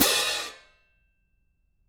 cymbal-crashshort_v1.wav